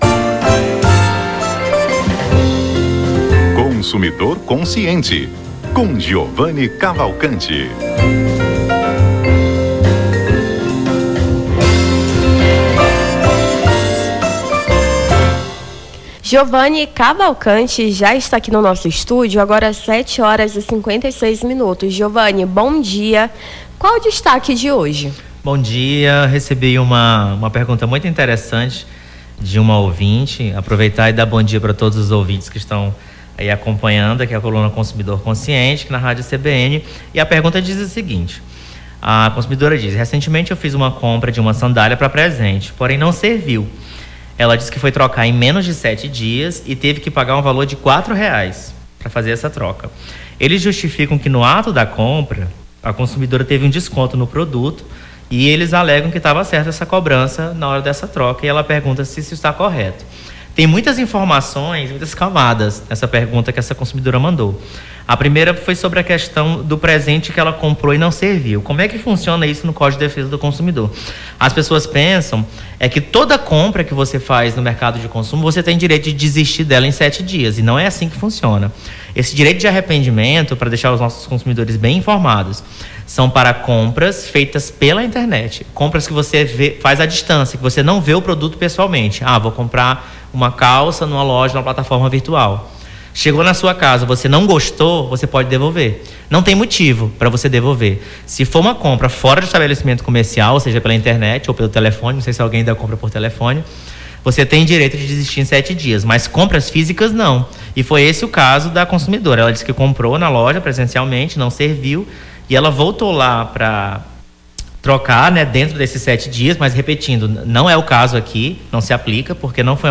Consumidor Consciente: advogado tira-dúvidas sobre o direito do consumidor